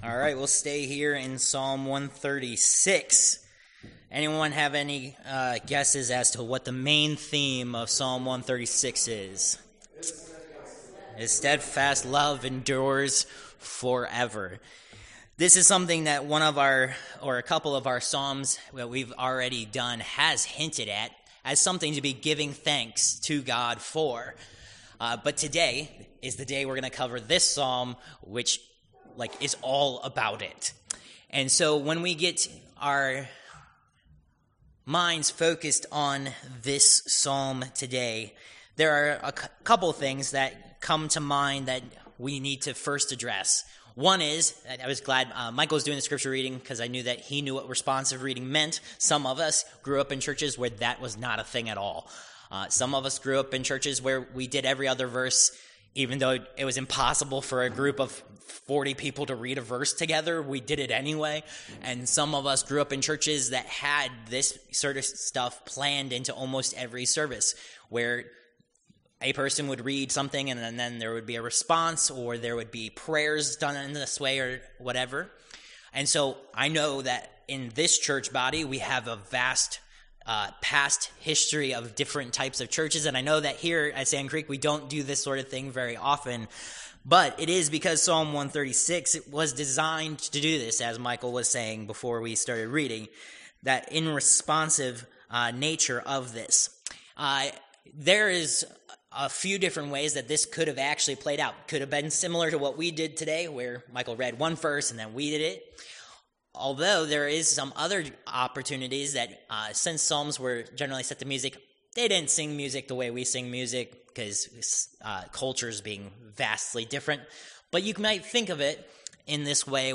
Ps. 136 Service Type: Worship Service Topics: Thanksgiving